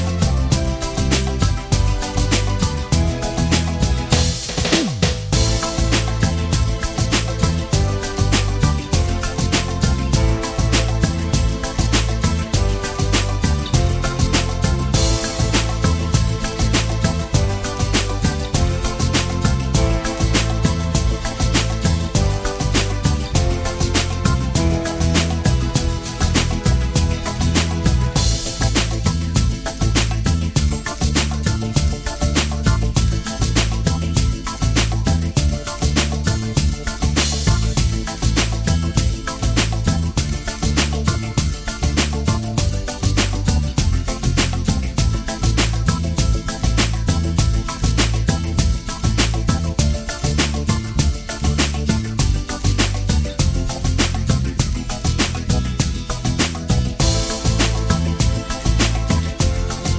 No Lead Guitar For Guitarists 2:42 Buy £1.50